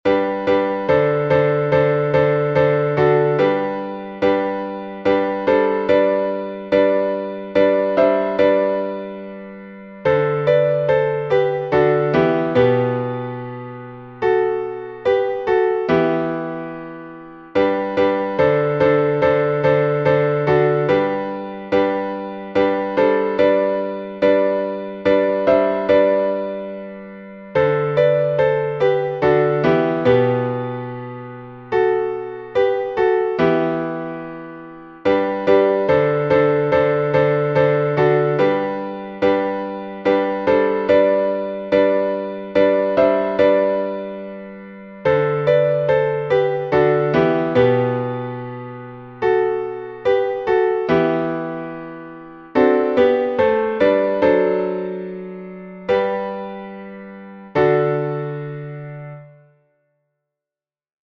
Киевский распев, глас 8